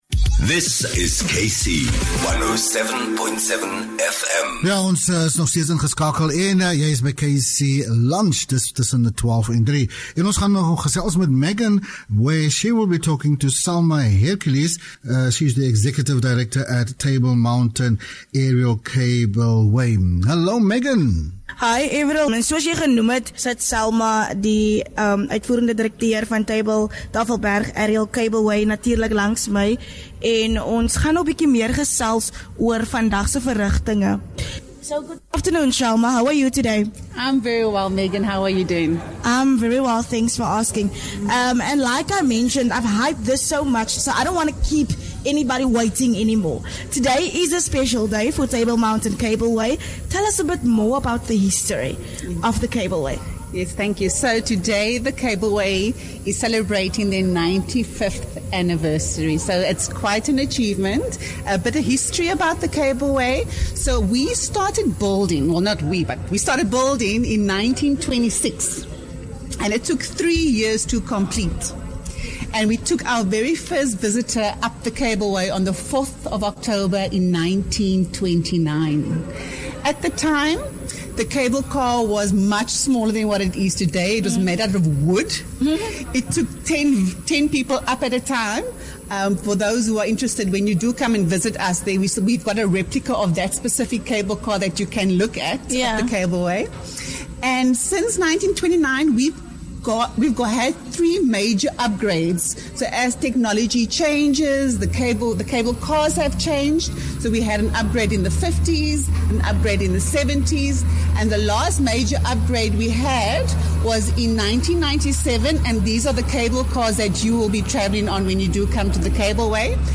9 Oct KC107.7 LIVE from Table Mountain- 4 Oct 24